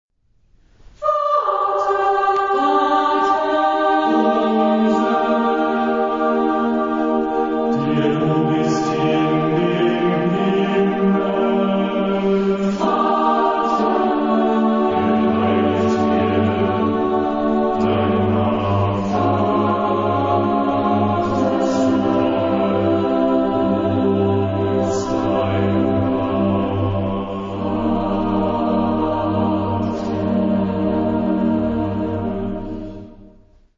SATB (4 voices mixed) ; Full score.
Contemporary. Sacred.